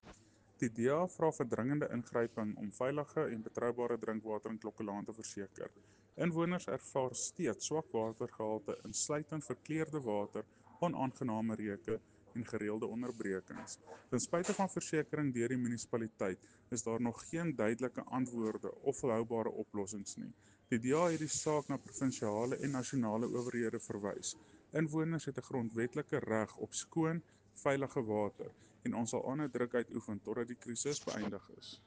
Afrikaans soundbites by Cllr Jose Coetzee and